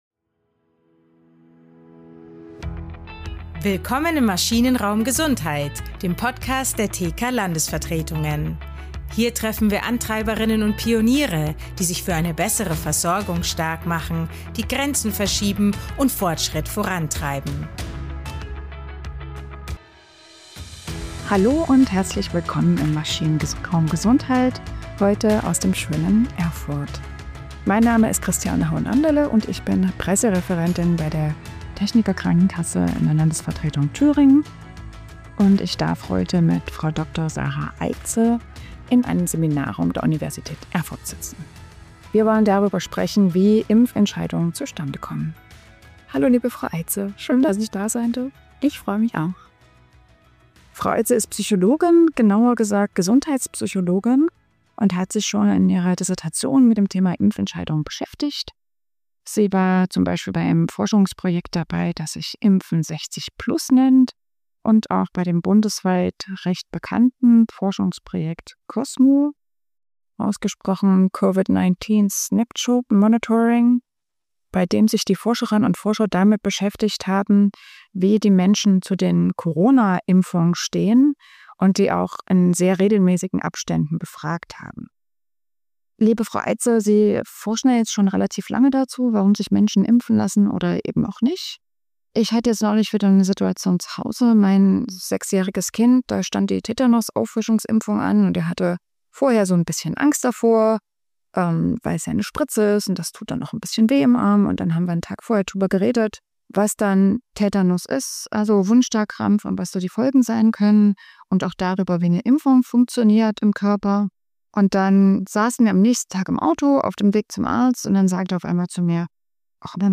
Podcast-Studio Berlin